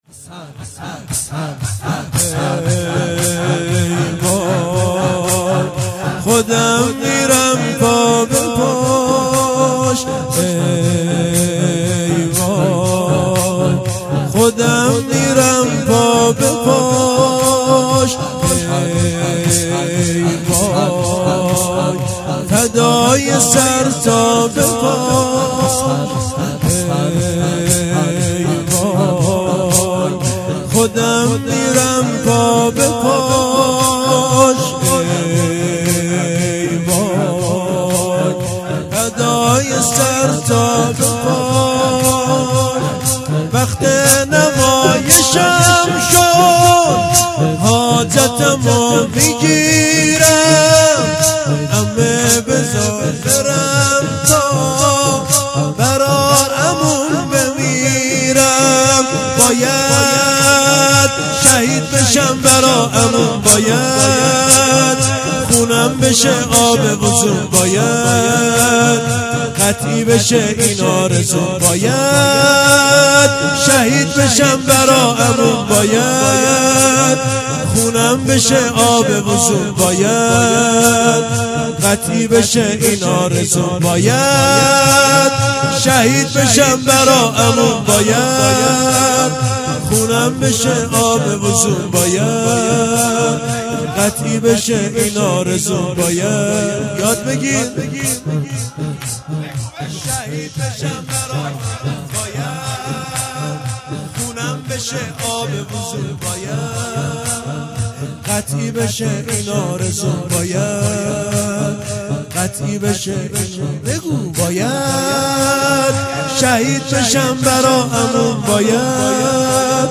شب پنجم محرم - زمینه - ای وای خودم میرم پا به پاش ای وای فدای سرتا به پاش
نوای جانسوز